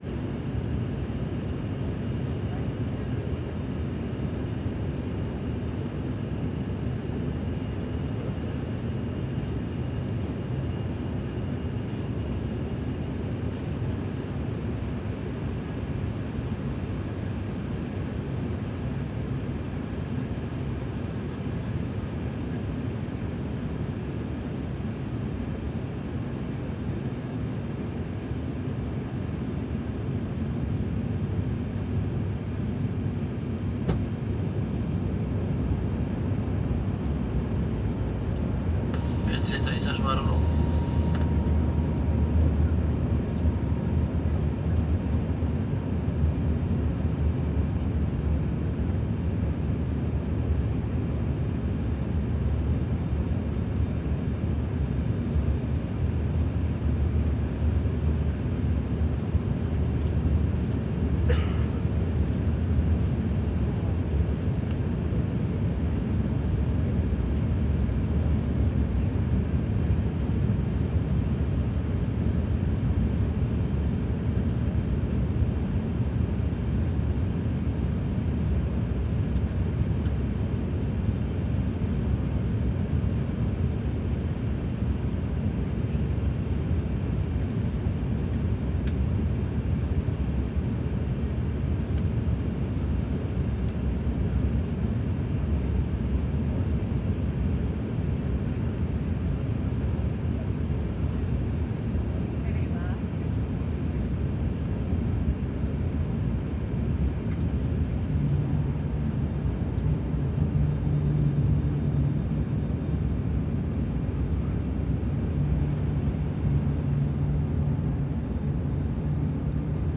Реалистичные записи передают гул двигателей, скрип тормозов и другие детали, создавая эффект присутствия на взлетно-посадочной полосе.
Звук посадки самолета внутри салона (полный цикл) (04:47)